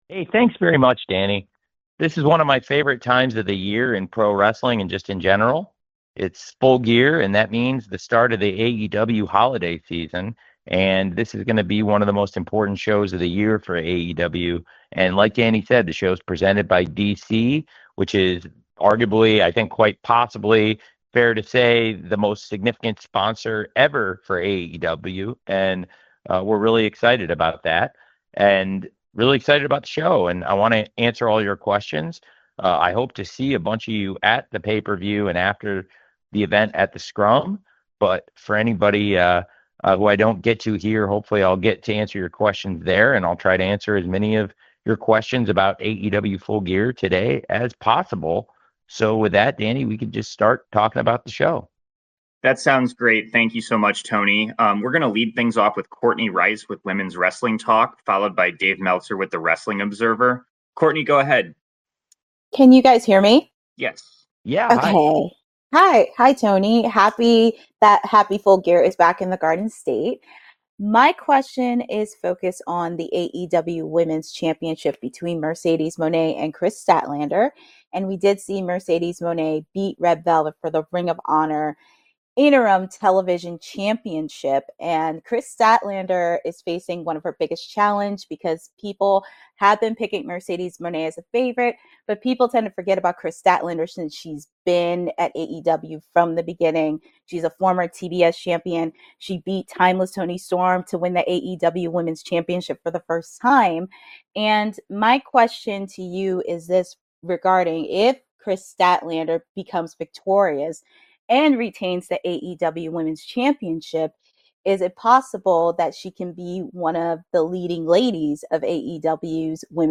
AEW Full Gear 2025 Media Call with Tony Khan
Tony Khan speaks with the media ahead of AEW Full Gear 2025 taking place on Saturday, November 22, 2025 at the Prudential Center in Newark, New Jersey.